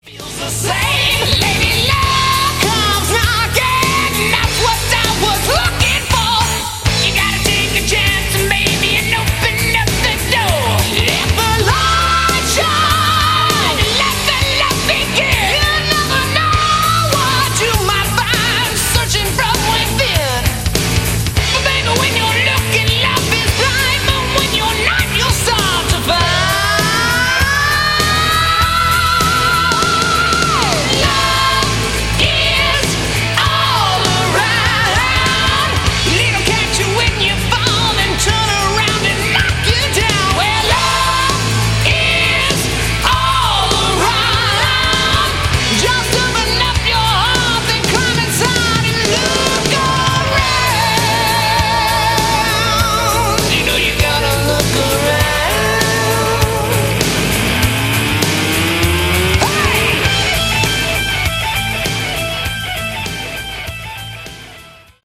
Category: Hard Rock
lead vocals
keyboards, vocals
bass, vocals
drums